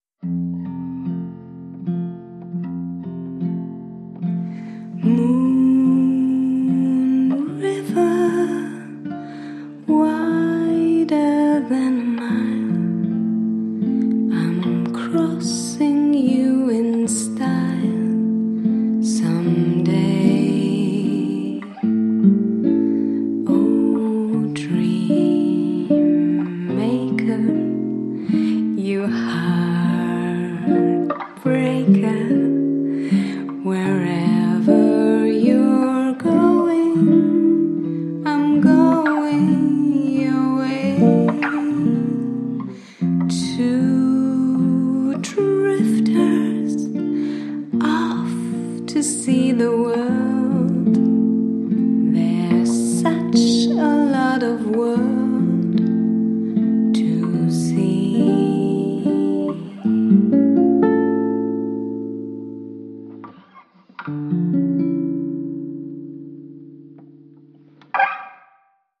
Sprachproben
Female